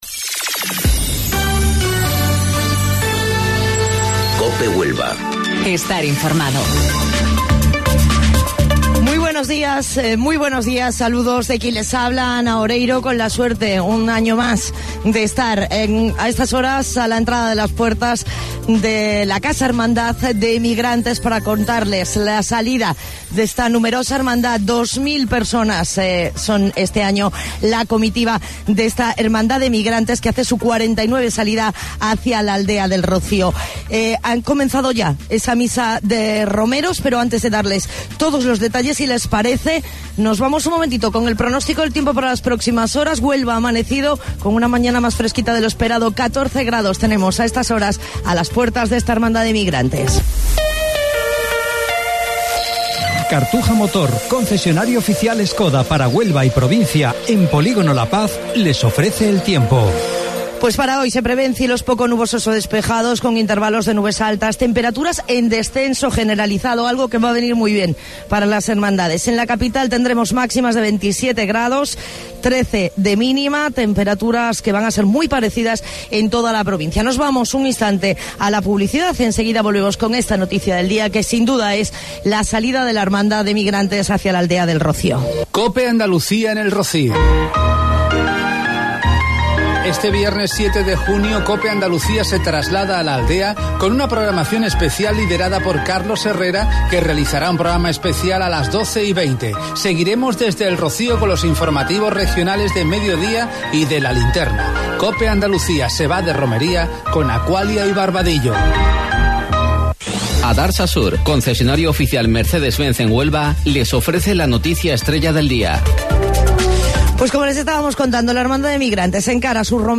AUDIO: Informativo Local 07:55 del 5 de Junio